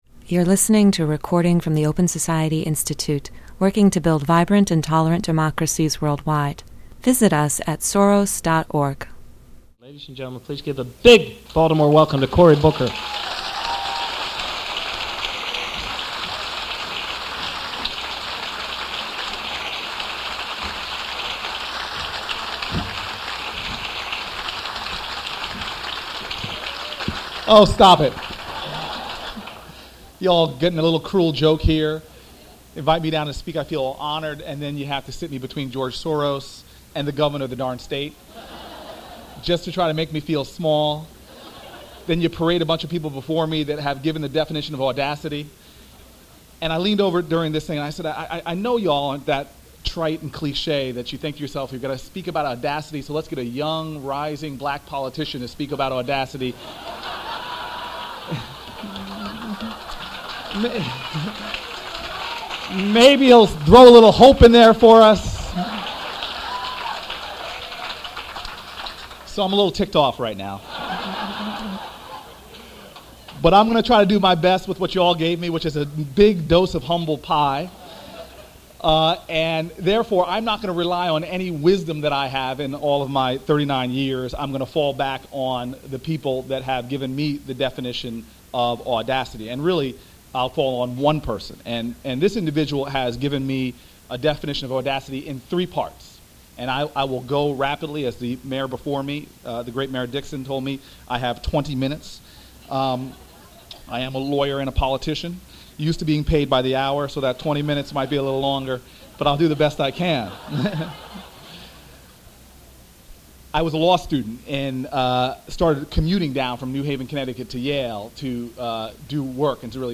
Cory Booker Opens the Open Society Institute–Baltimore’s Tenth Anniversary Celebration